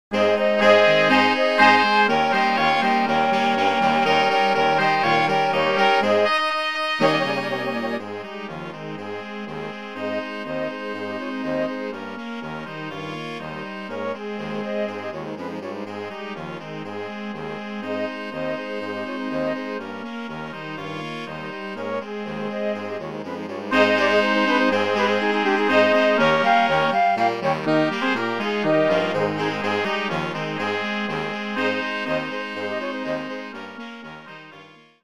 Orkiestrowa